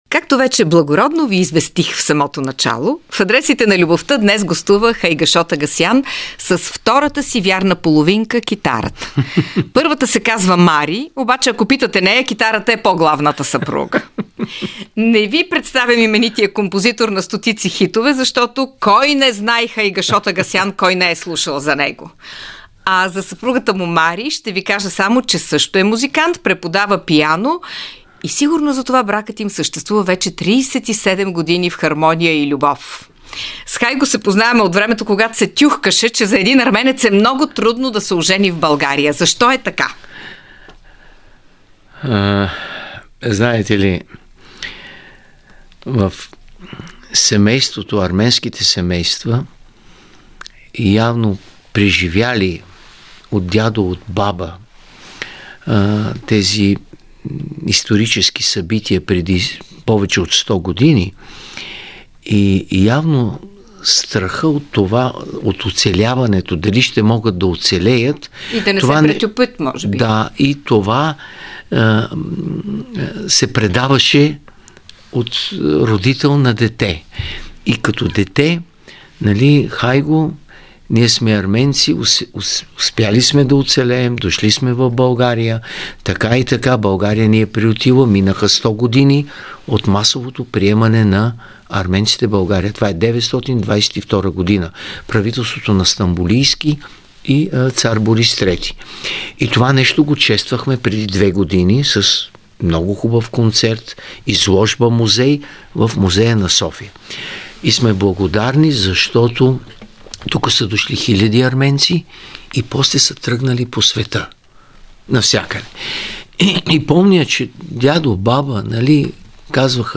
В Адресите на любовта композиторът Хайгашод Агасян гостува с втората си вярна половинка – китарата.